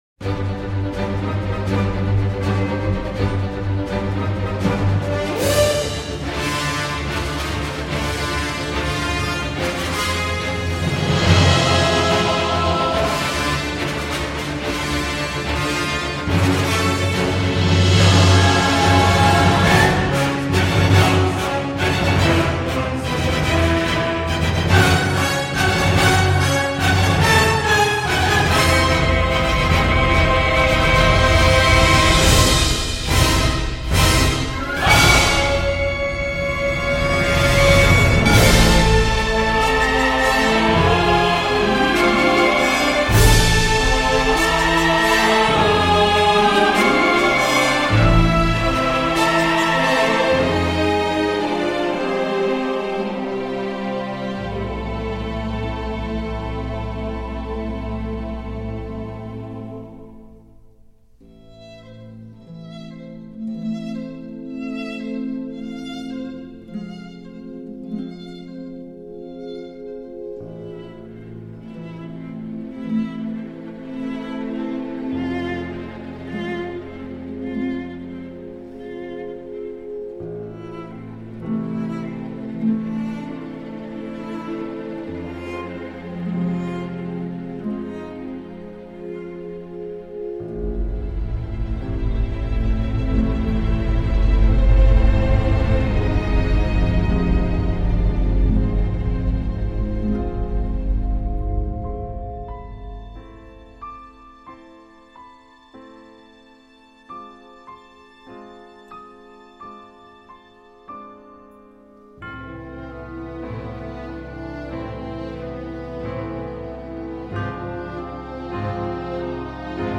À grand renfort de chœurs et de voix féminines